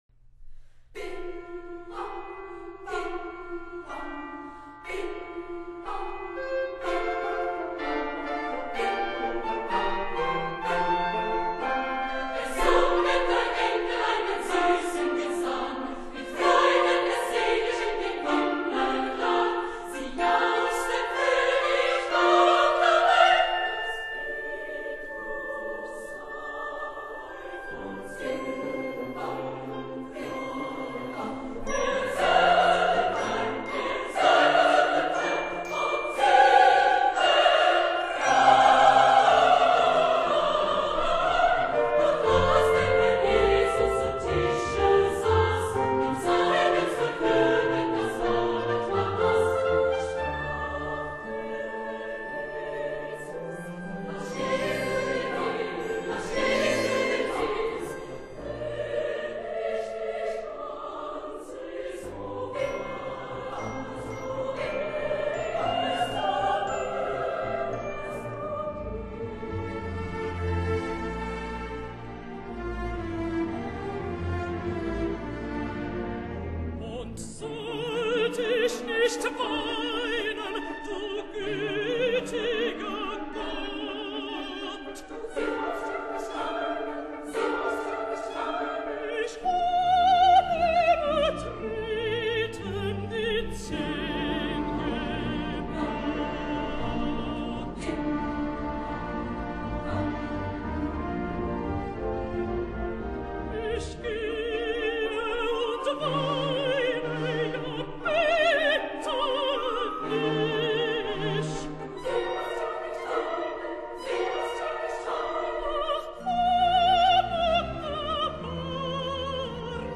此段富有建设性的地方，莫过于半音阶的洪亮的弦乐部分所形成的清新愉快乐章。